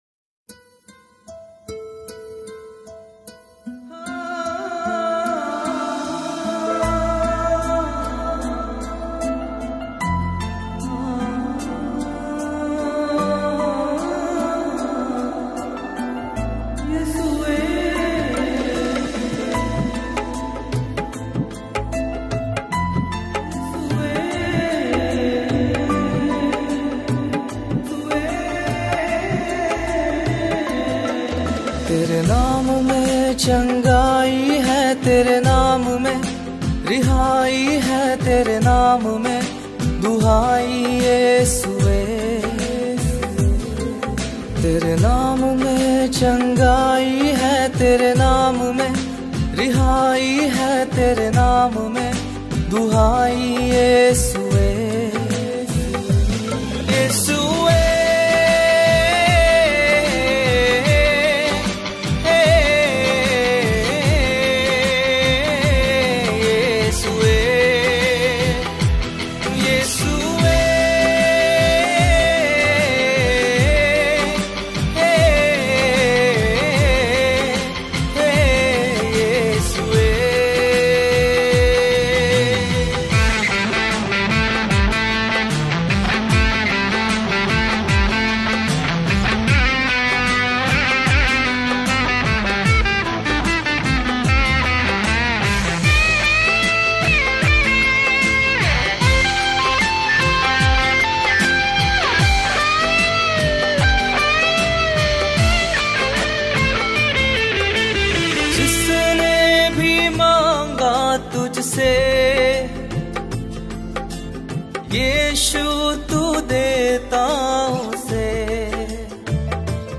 Listen and download Gospel songs